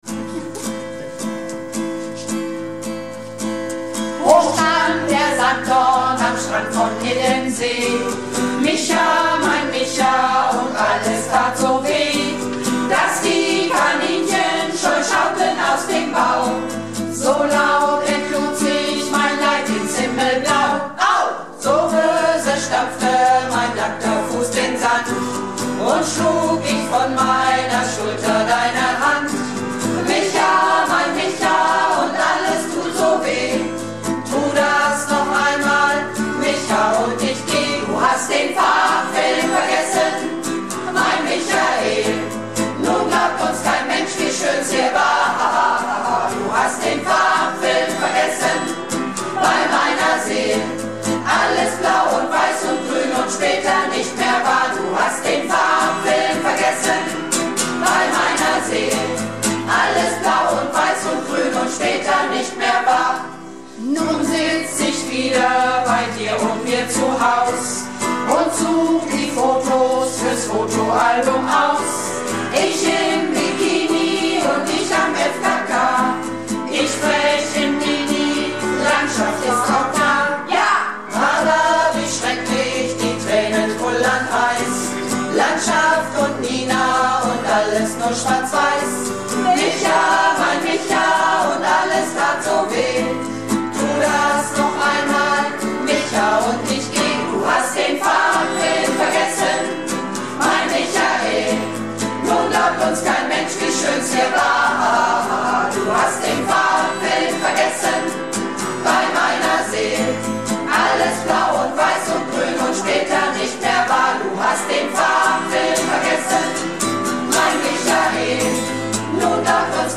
Malle Diven - Probe am 02.02.16